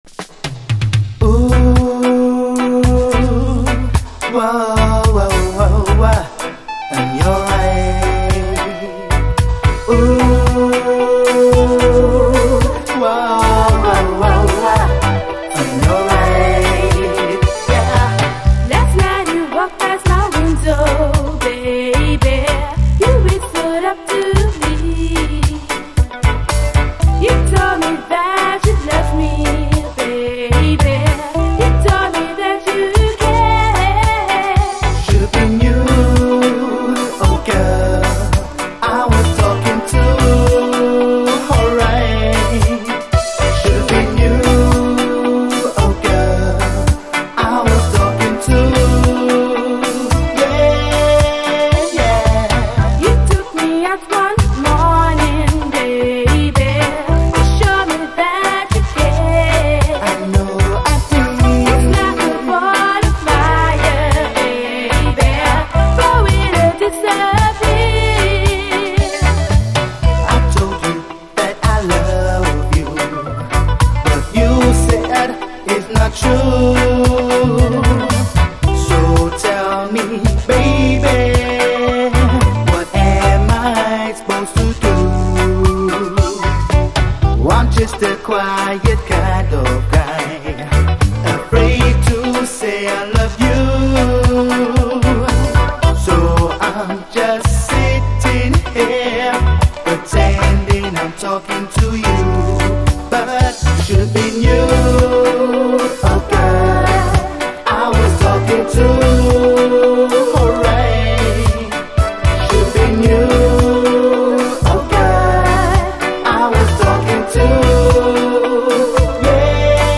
REGGAE
奇跡のキラー・オブスキュアUKラヴァーズ！
後半にダブへ接続。
特に中盤のギター＆トランペット＆ピアノ・ソロがめちゃくちゃ美しいので必聴です。